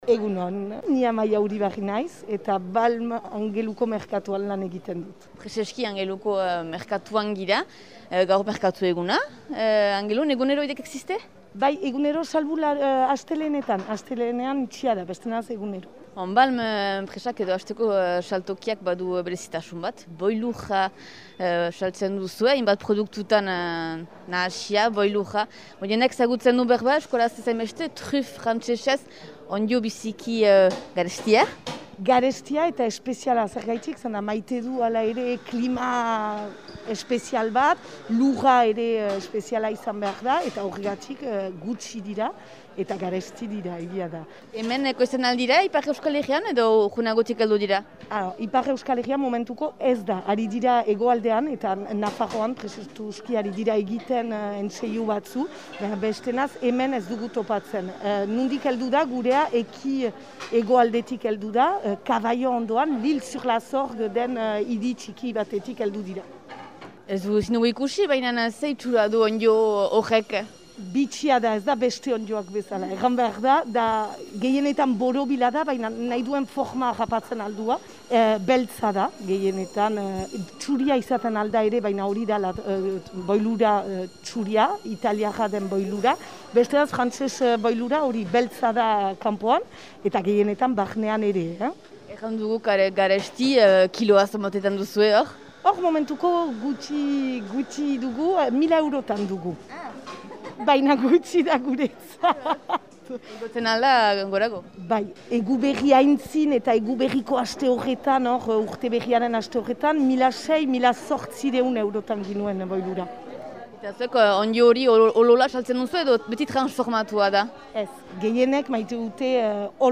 mikroarekin.